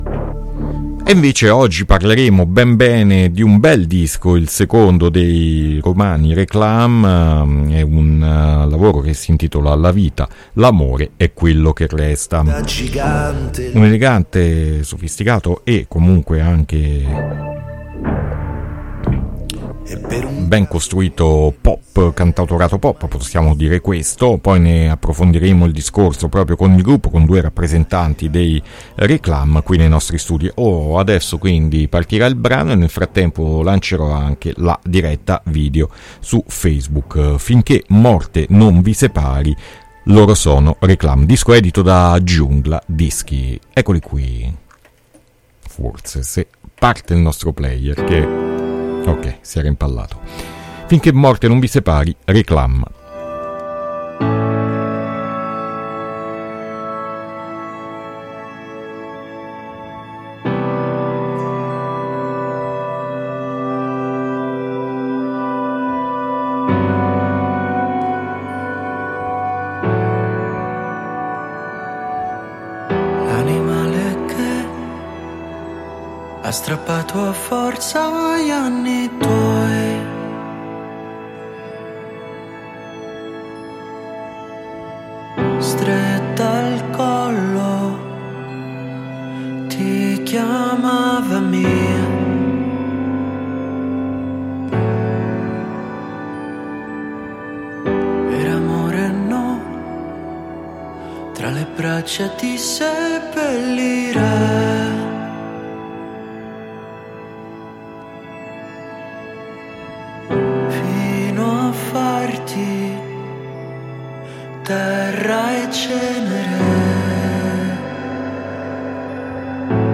Intervista Réclame ad Alternitalia 3-6-2022